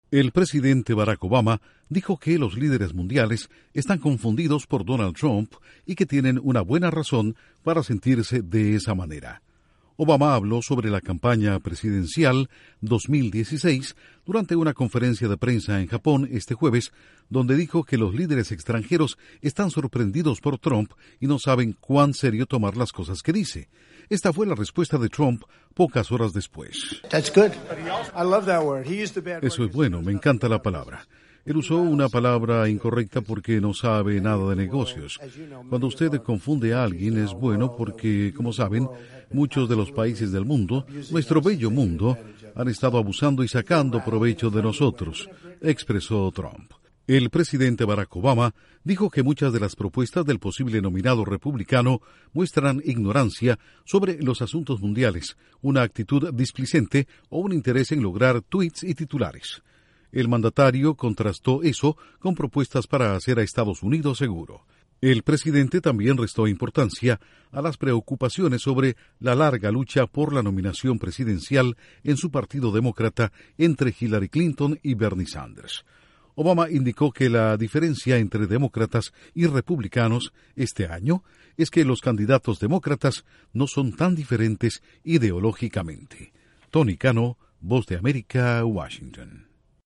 Trump le responde al presidente Obama, quien dijo durante una reunión del G-7 en Japón, que líderes mundiales están “confundidos” con el precandidato presidencial republicano. Informa desde la Voz de América